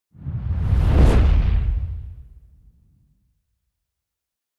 جلوه های صوتی
دانلود صدای آتش 17 از ساعد نیوز با لینک مستقیم و کیفیت بالا
برچسب: دانلود آهنگ های افکت صوتی طبیعت و محیط دانلود آلبوم صدای شعله های آتش از افکت صوتی طبیعت و محیط